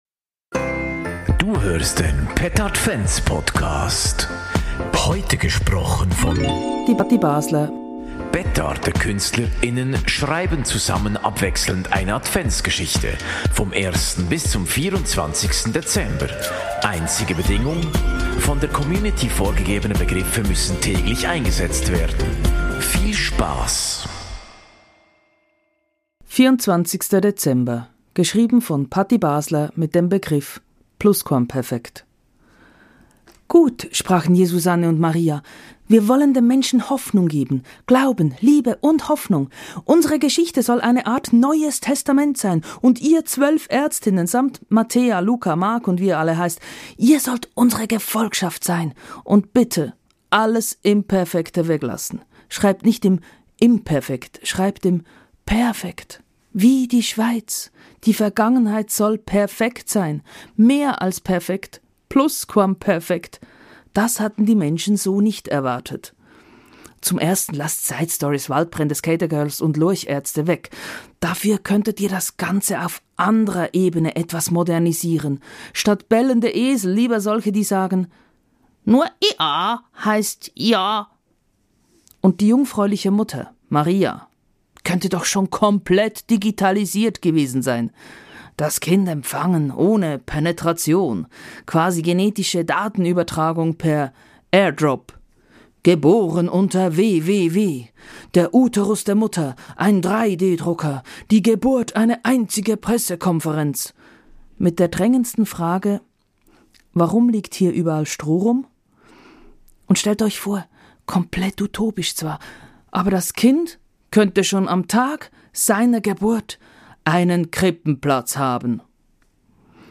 Petardekünstler:innen schreiben abwechselnd eine Adventsgeschichte
Gelesen von Patti Basler Jingles